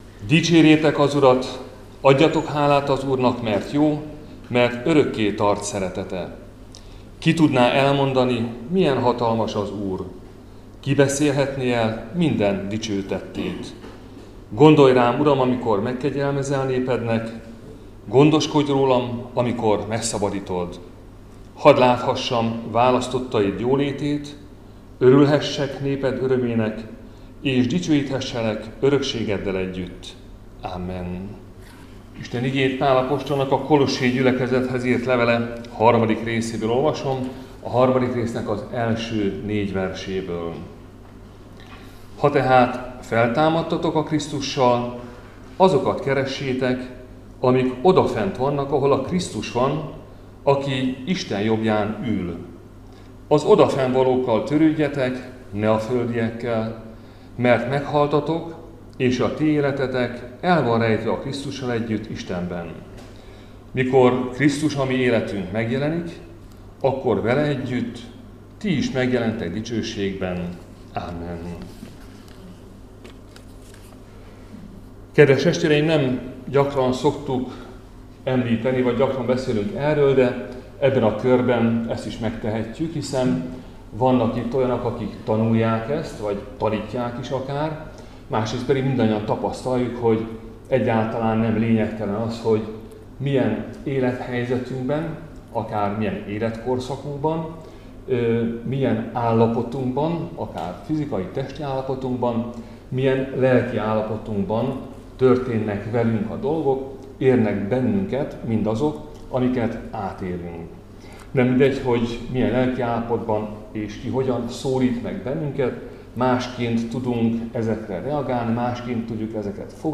Dunamelléki Református Egyházkerület – Áhítat, 2025. szeptember 16.